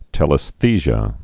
(tĕlĭs-thēzhə)